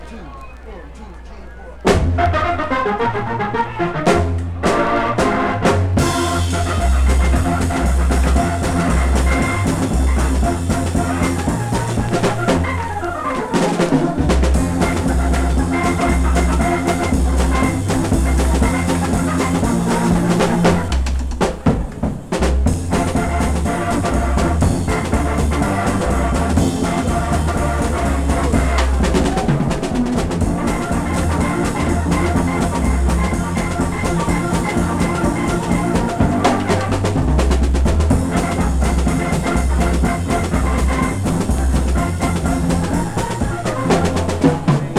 Rock, Rock'nRoll, Twist, Pop　USA　12inchレコード　33rpm　Stereo